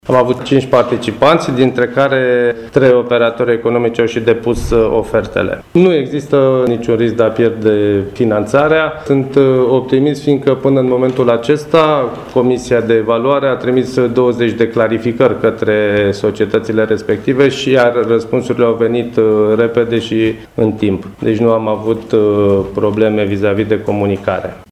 Primarul Râşnovului, Liviu Butnariu este mulţumit de derularea etapelor de până acum şi optimist în legătură cu respectarea programului proiectului de restaurare: